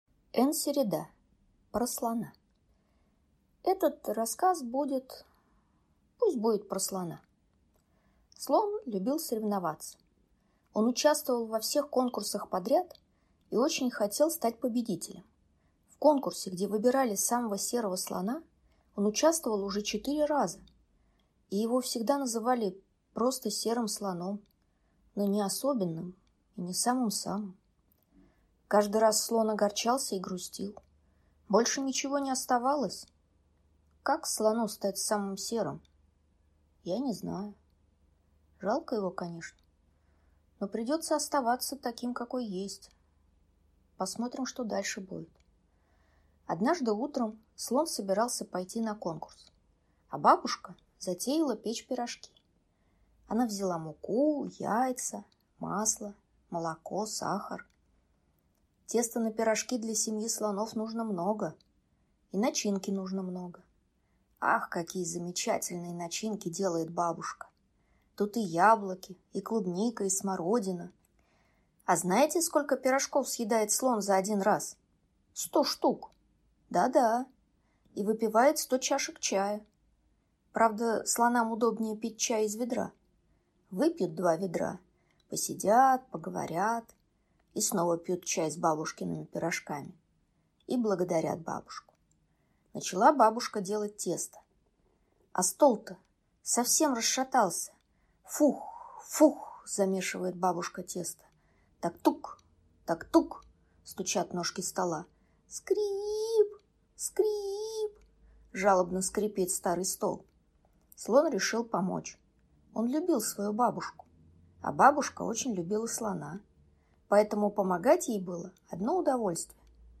Аудиокнига «Про Слона».